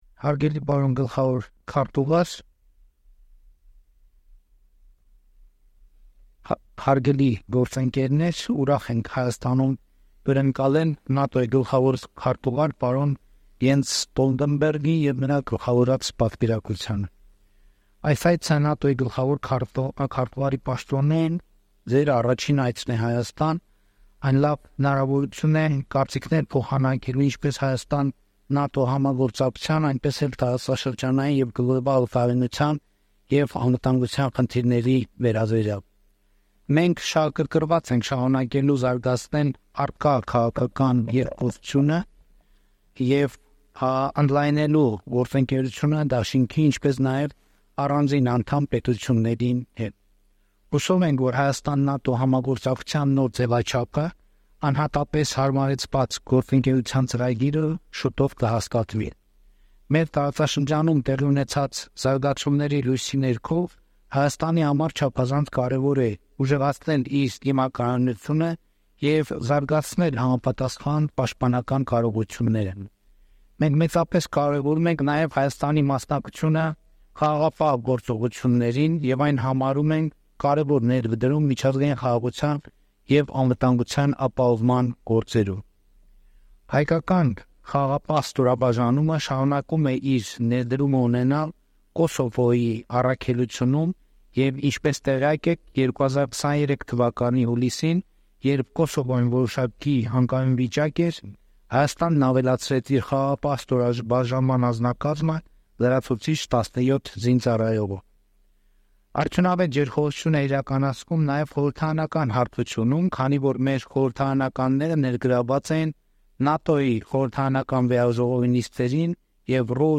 Joint press statements